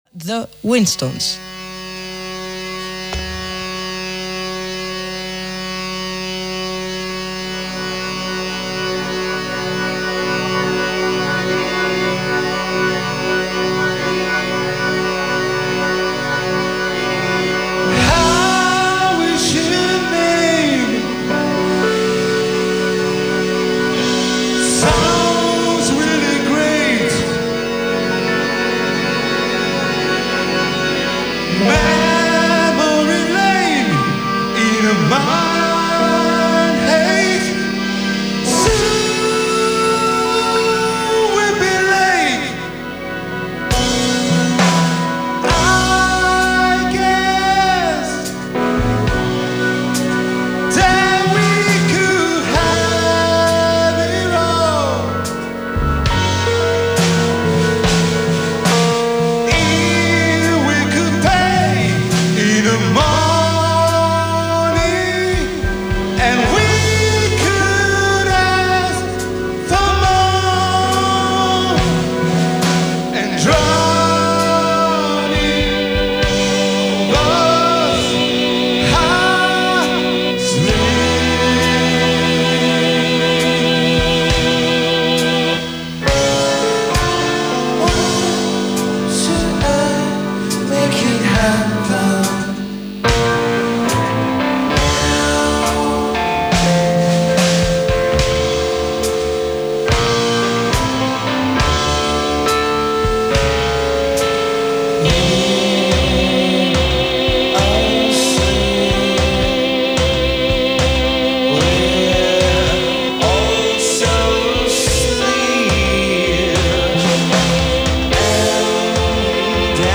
live at The Monk Club, Rome
Drums, Bass/guitar and Organ
Nu-Psych